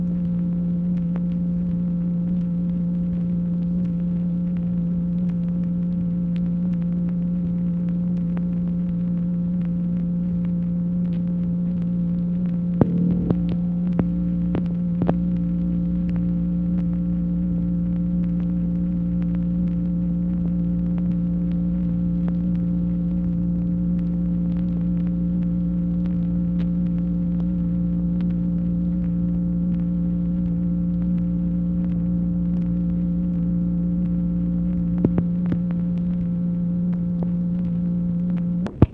MACHINE NOISE, March 1, 1966
Secret White House Tapes | Lyndon B. Johnson Presidency